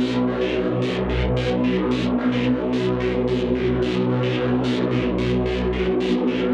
Index of /musicradar/dystopian-drone-samples/Tempo Loops/110bpm
DD_TempoDroneC_110-B.wav